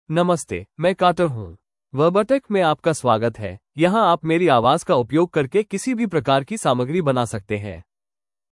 Carter — Male Hindi (India) AI Voice | TTS, Voice Cloning & Video | Verbatik AI
MaleHindi (India)
Carter is a male AI voice for Hindi (India).
Voice sample
Listen to Carter's male Hindi voice.
Carter delivers clear pronunciation with authentic India Hindi intonation, making your content sound professionally produced.